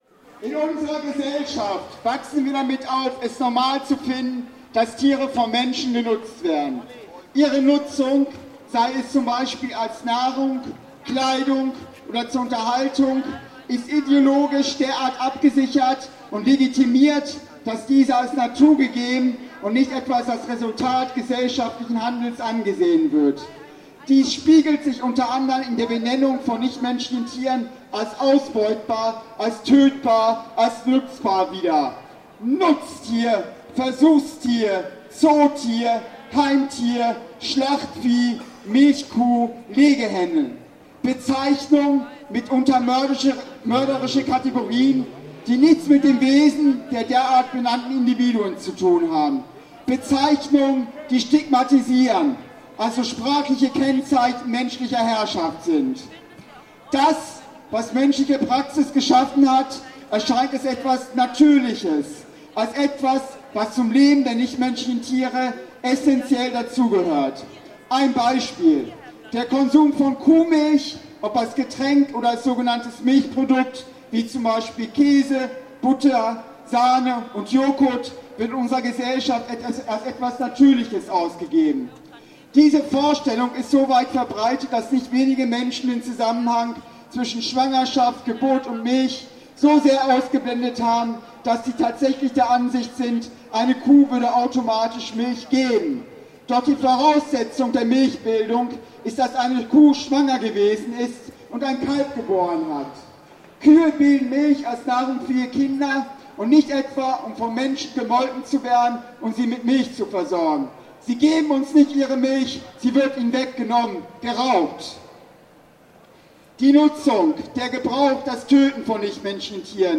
Einige auf der Demonstration vor tierausbeutenden Restaurants und Läden gehaltene Ansprachen:
Die 2. Ansprache (Audio 2/4) [MP3]